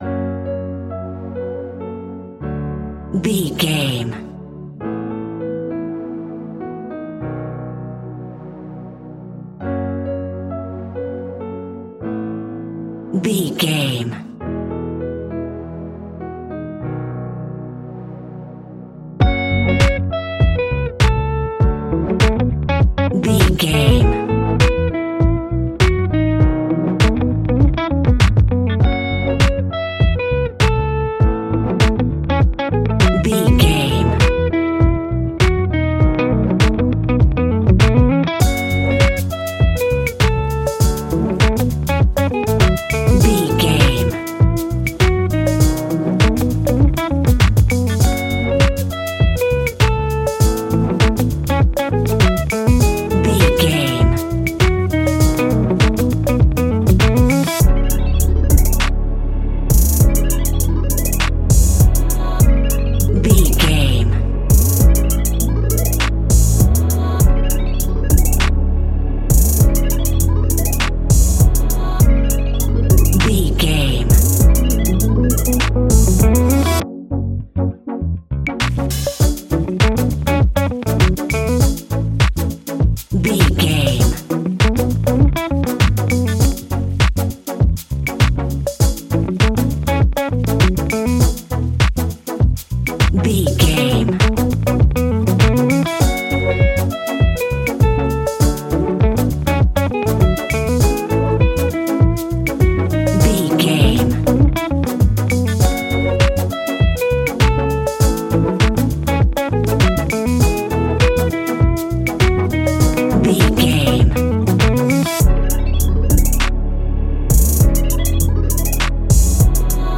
A Melodic Dance Song
Epic / Action
Fast paced
In-crescendo
Uplifting
Aeolian/Minor
F♯
dreamy
futuristic
bouncy
ethereal
funky
mellow
bass guitar
electric guitar
saxophone
synthesiser
drum machine
electric organ
electric piano
strings